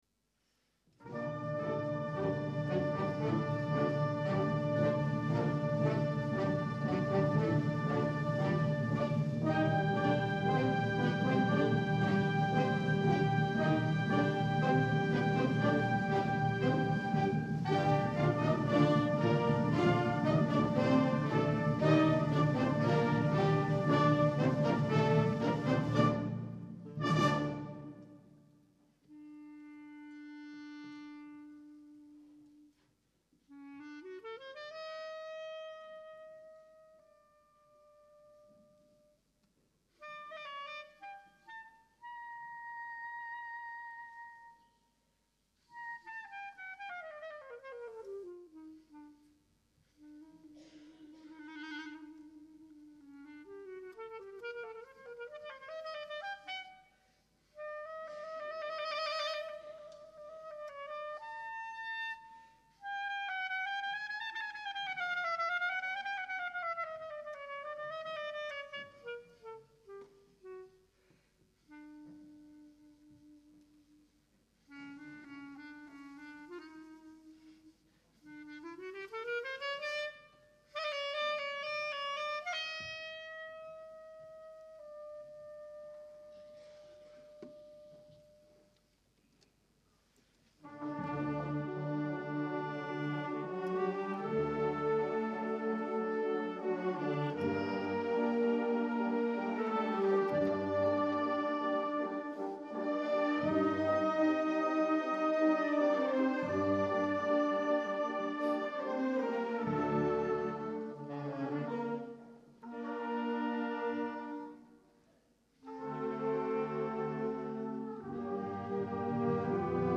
I Brani Musicali sono stati registrati al Teatro "A. Bonci" di Cesena il 18 Febbraio 2001 durante il
CONCERTO LIRICO
Banda "Città di Cesena"